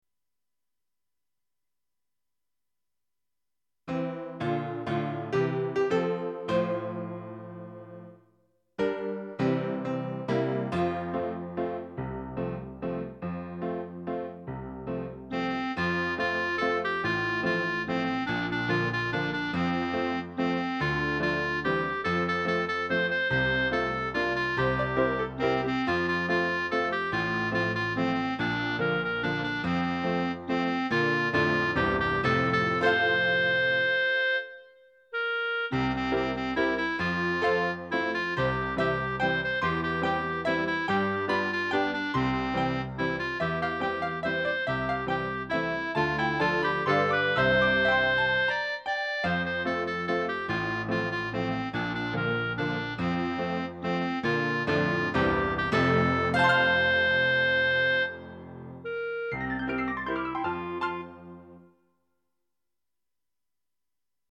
この時期に作成した下記の演奏は、「ポラーノの広場」の中に出てくる「牧者の歌（けさの六時ころ ワルトラワラの）」を、Roland の SC-8850という音源モジュールで音にしたものです。
「ポラーノの広場」の物語中では、少年ミーロがクラリネットと一緒に歌うことになっているので、上の演奏もホンキートンク調のピアノ伴奏に、クラリネットという組み合わせになっています。